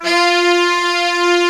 SAX F3.wav